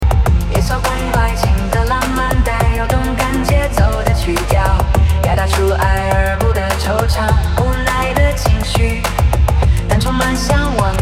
一首关于爱情的浪漫带有动感节奏的曲调，表达出爱而不得惆怅无奈的情绪，但充满向往